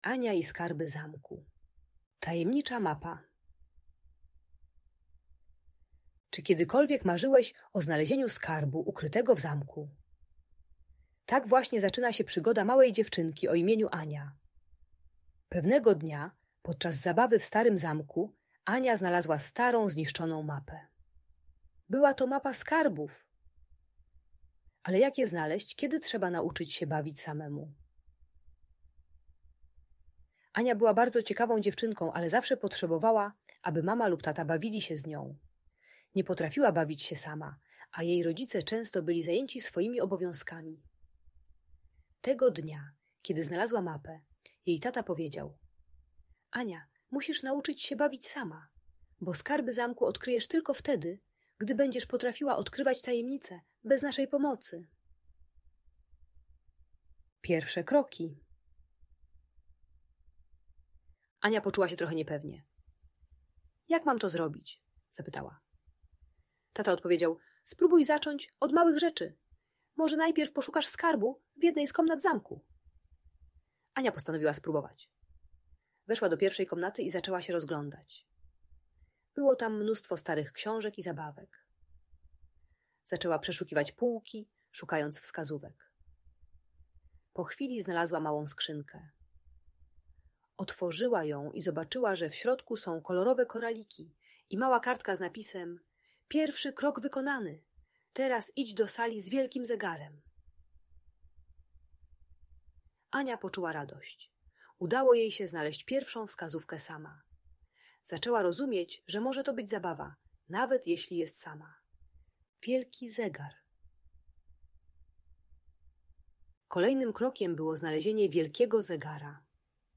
Ania i Skarby Zamku - Audiobajka